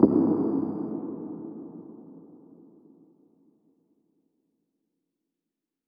AV_Impact_FX
AV_Impact_FX.wav